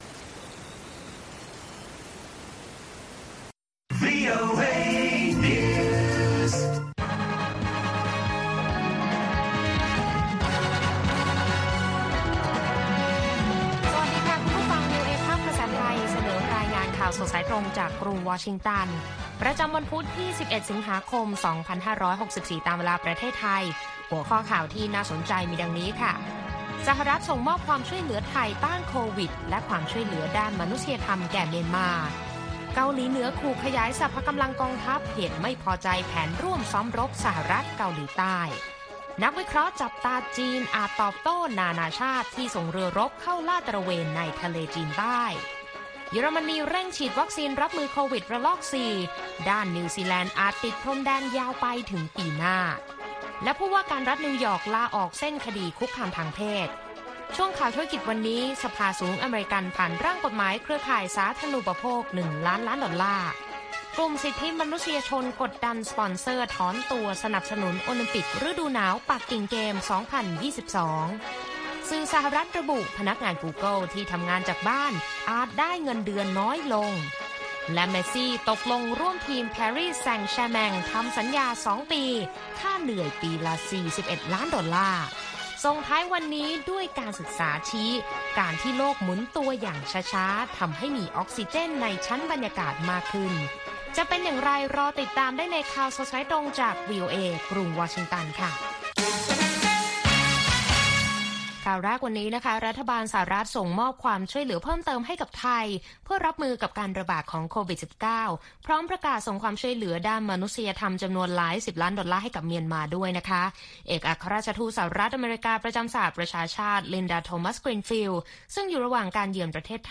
ข่าวสดสายตรงจากวีโอเอ ภาคภาษาไทย ประจำวันพุธที่ 11 สิงหาคม 2564 ตามเวลาประเทศไทย